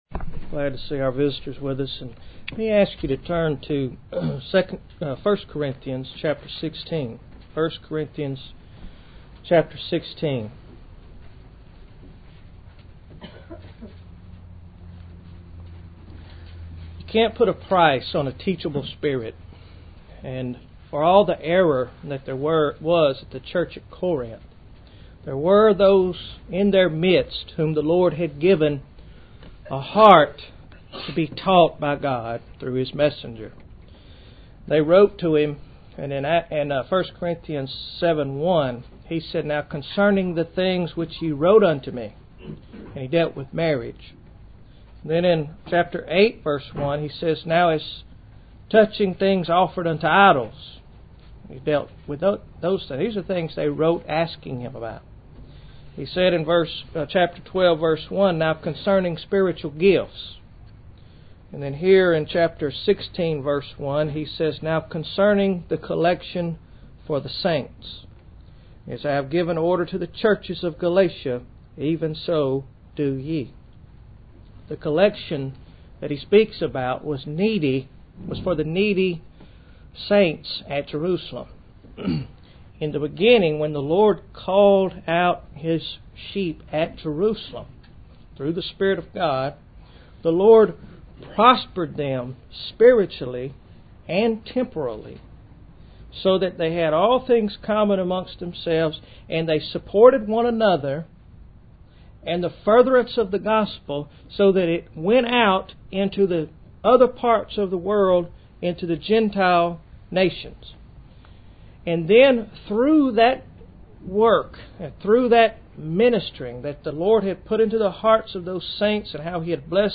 Date 22-Aug-2010 Article Type Sermon Notes PDF Format pdf Word Format doc Audio HI-FI Listen: First Things First (32 kbps) Audio CD Quality Listen: First Things First (128 kbps) Length 47 min.